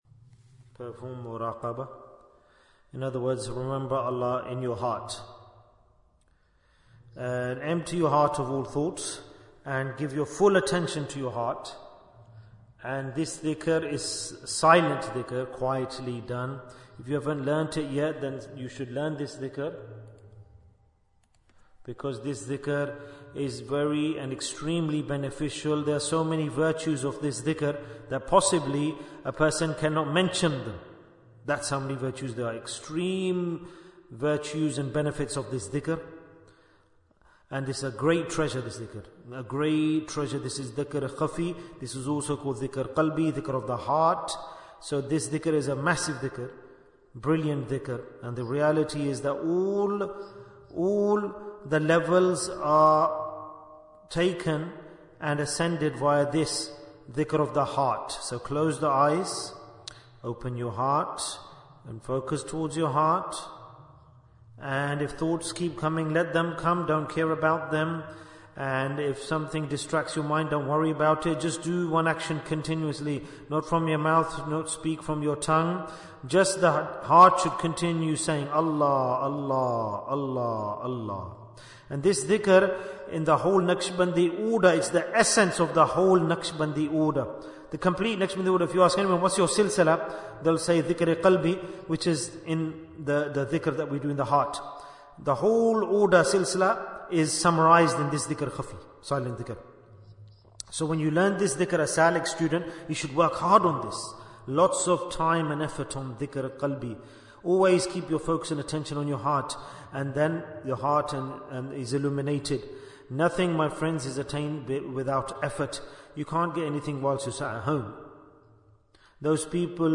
Talk before Dhikr 1226 minutes22nd November, 2024